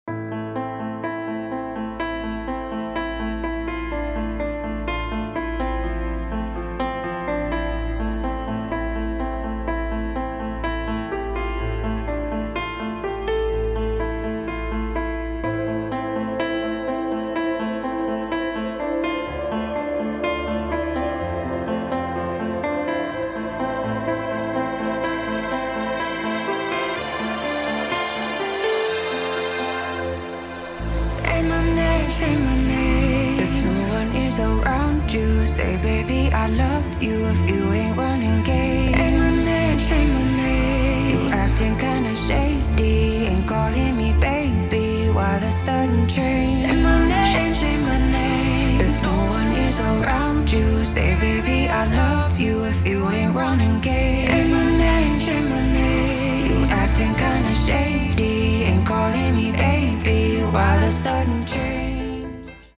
• Качество: 256, Stereo
мужской вокал
женский вокал
спокойные
клавишные
пианино